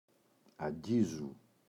αγγίζου [a’ŋgizu]